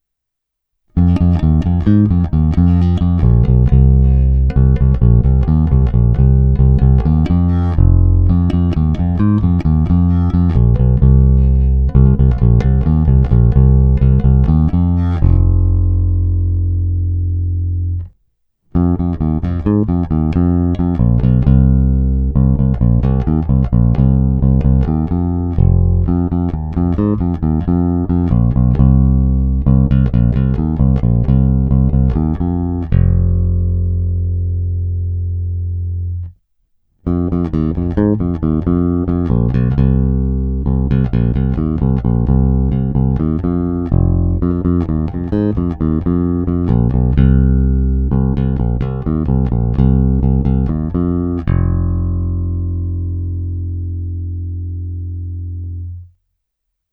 Xotic je spíš moderně pevný.
Není-li uvedeno jinak, následující nahrávky jsou provedeny rovnou do zvukové karty, jen normalizovány, jinak ponechány bez úprav. Tónová clona vždy plně otevřená, stejně tak korekce ponechány na středu.
Hra mezi snímačem a kobylkou